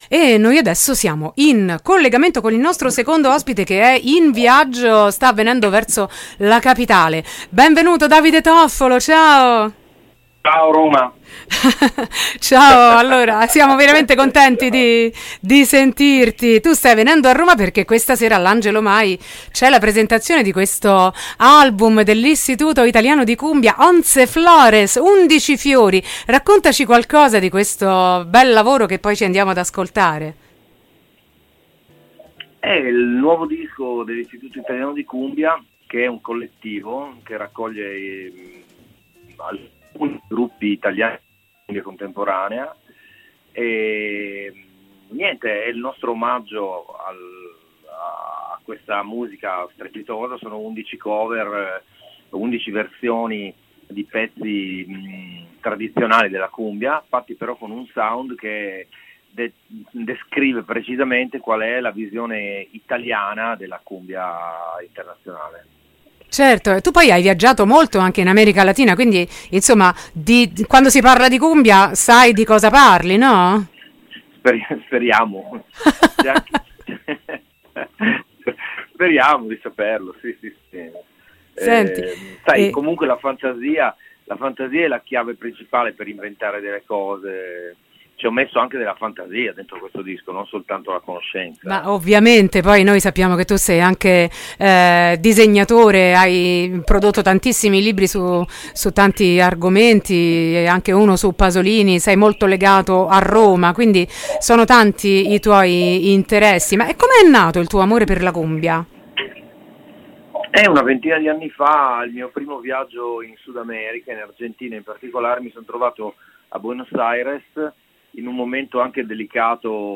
intervista-davide-toffolo-22-9-23.mp3